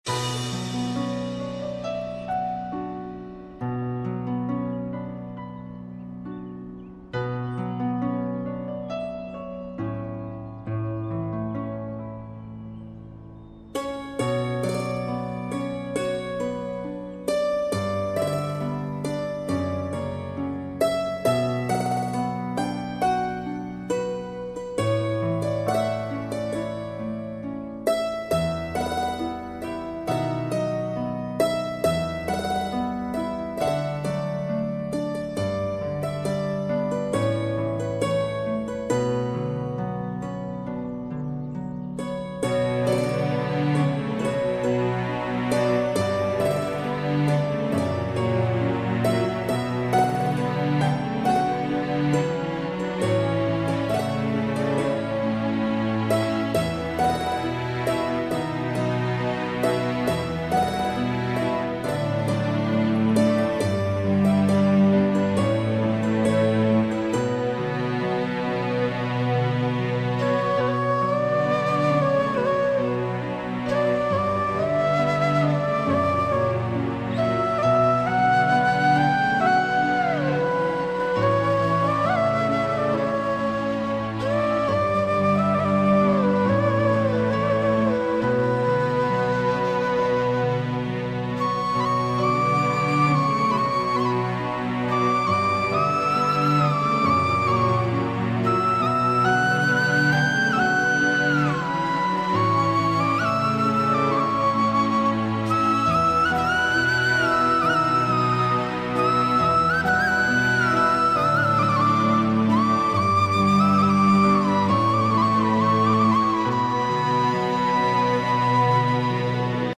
Tv Serials Full Songs